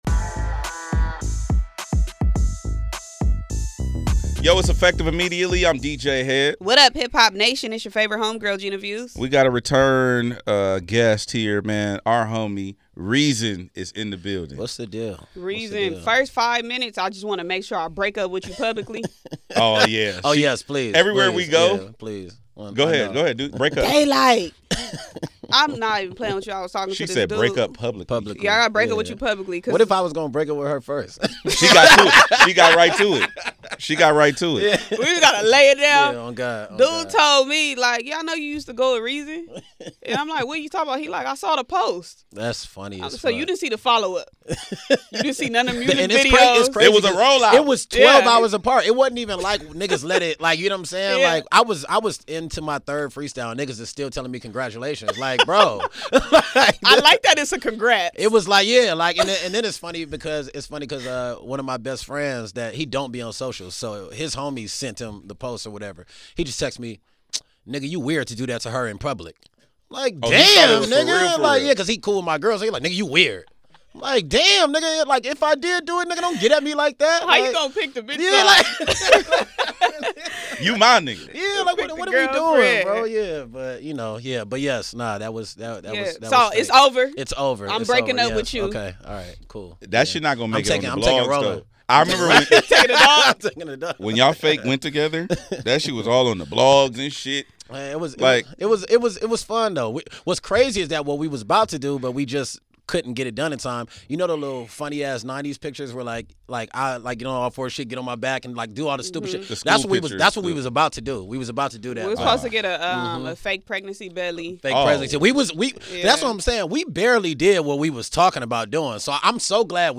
Rap Contest